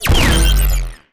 weapon_energy_beam_006.wav